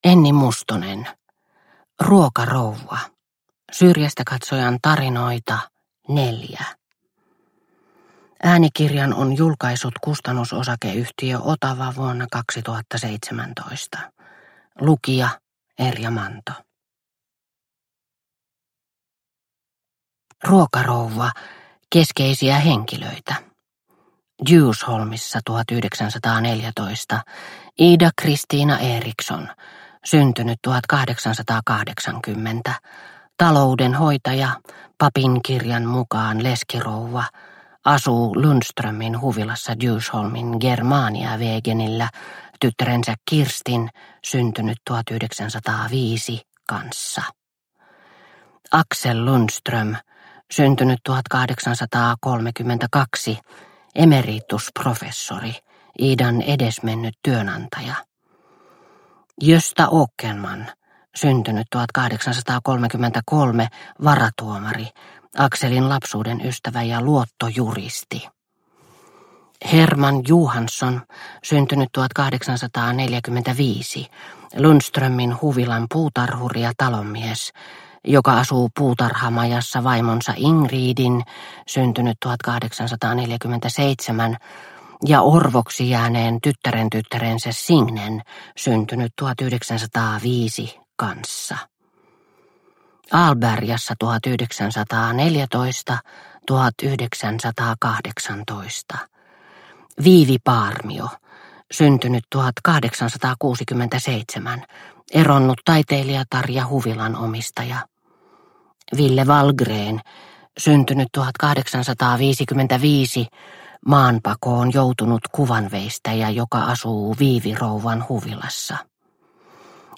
Ruokarouva – Ljudbok – Laddas ner